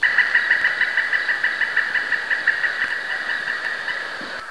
son_grand_pic.wav